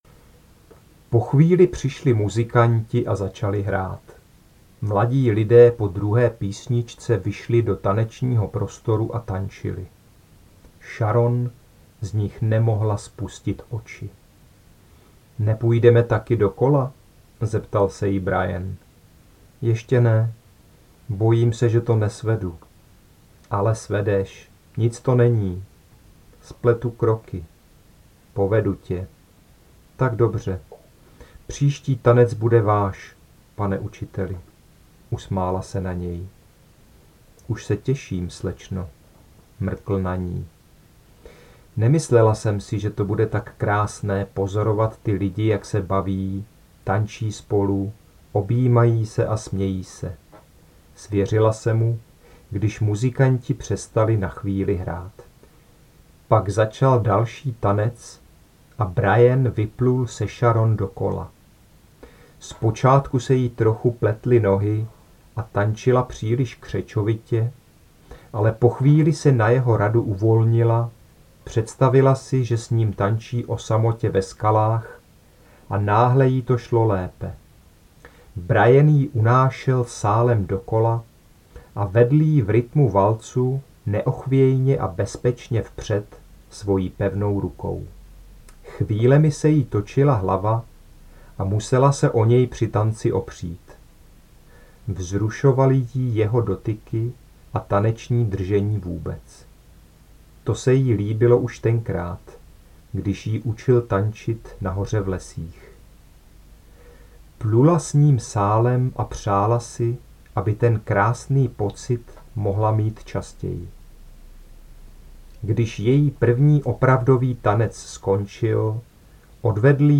• audiokniha v mp3    (